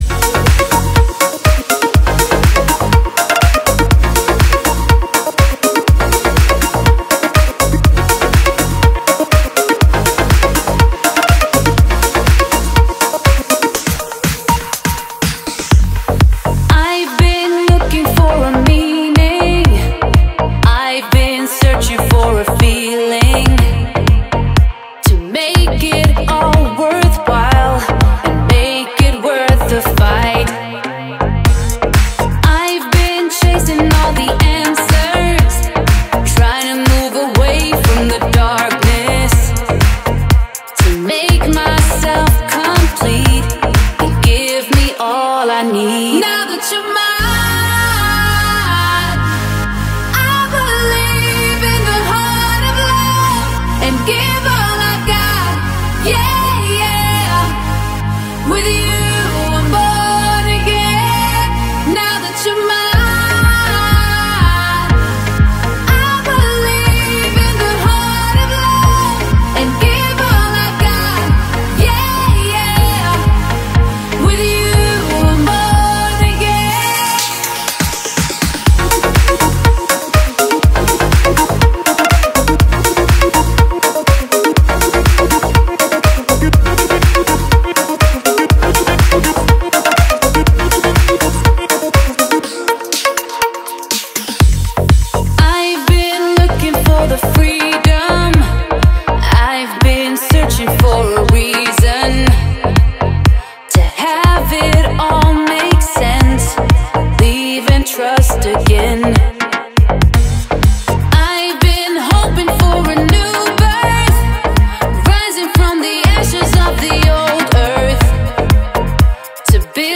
club_danceremix__.mp3